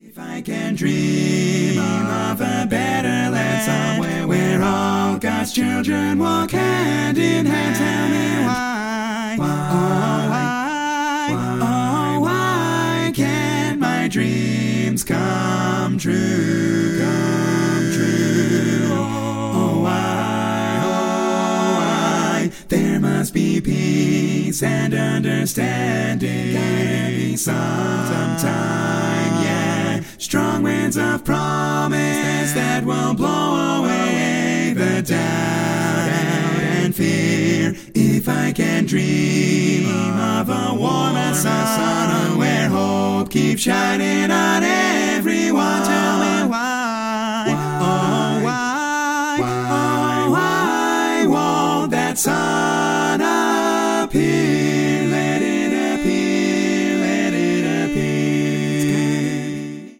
full mix
Category: Male